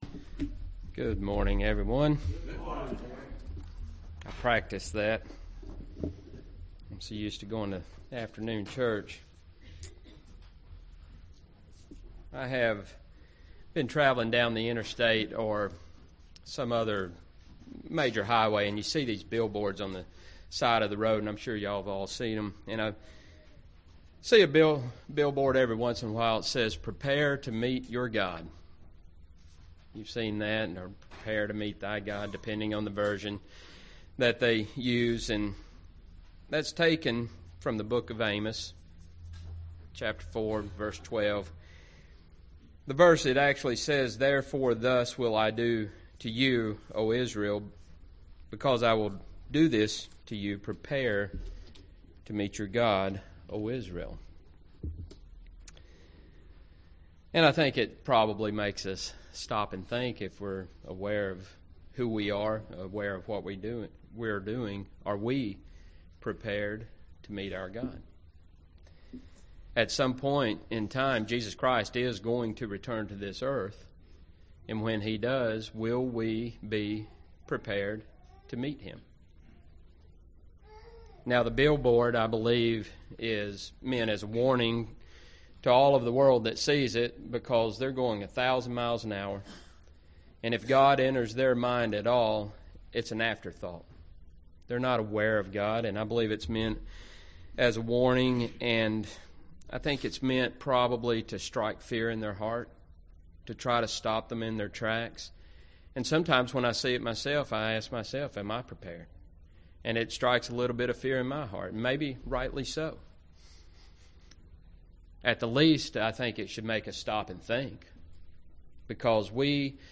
In this sermon we discuss how to prepare to meet God.
Given in Gadsden, AL